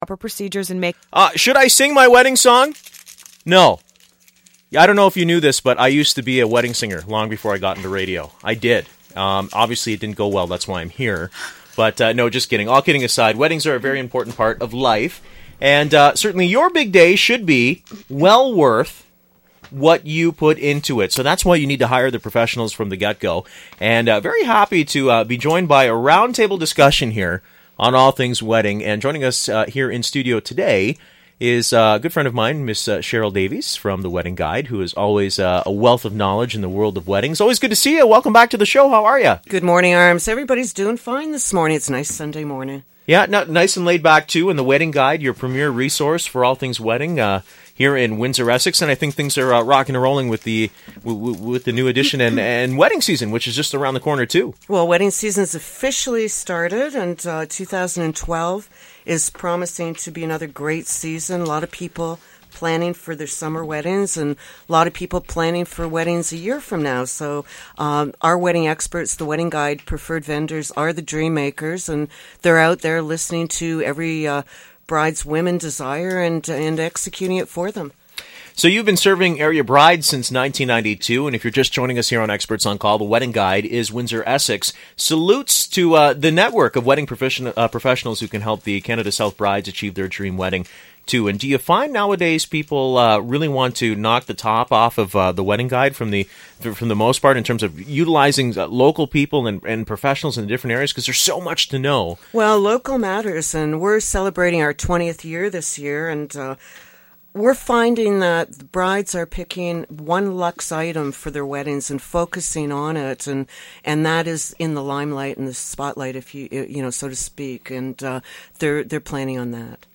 Wedding Guide Professionals Live on the Radio, April 22,2012